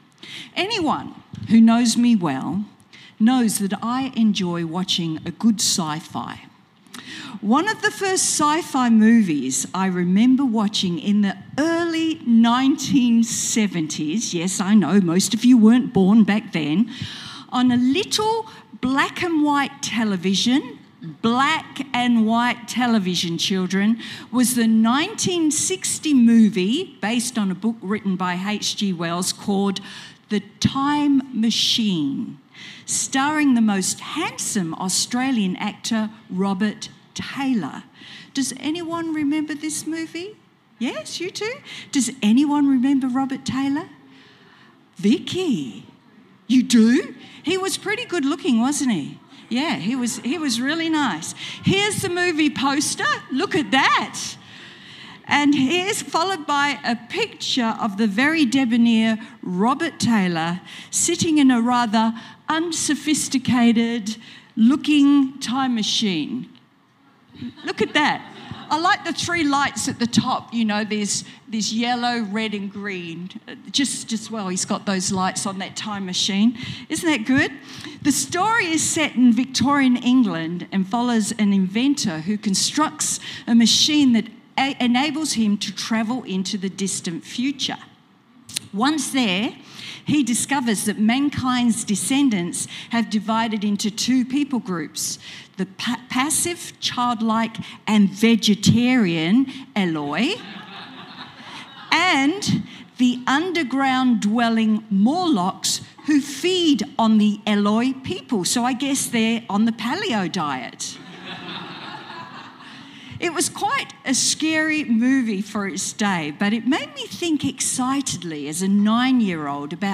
Sermon Transcript: Anyone who knows me well, knows that I enjoy watching a good sci-fi.